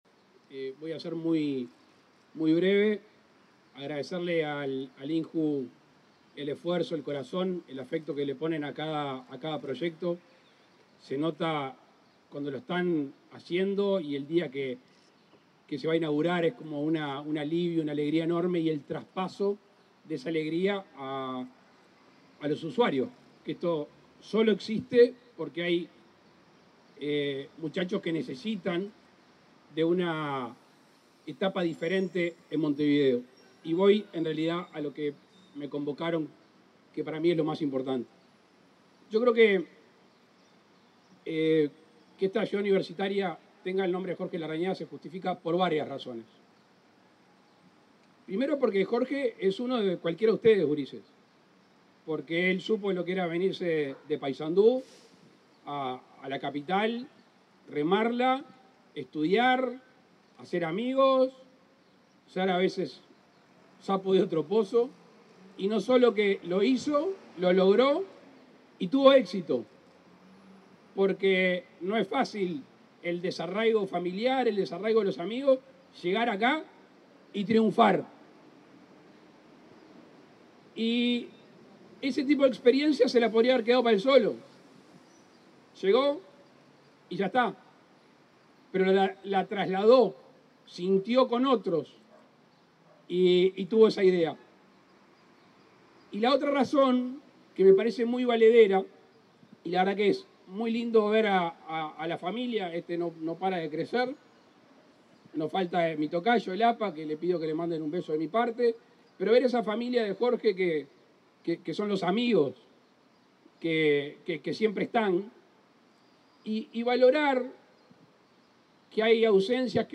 Palabras del presidente de la República, Luis Lacalle Pou
El presidente de la República, Luis Lacalle Pou, participó este 24 de octubre en la inauguación del edificio Jorge Larrañaga, en el marco del programa